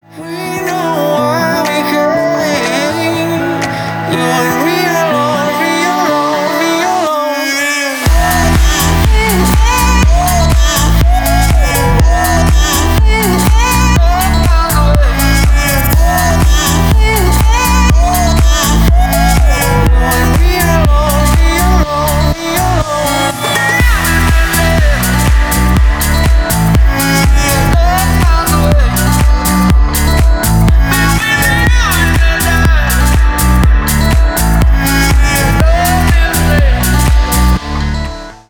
Танцевальные
клубные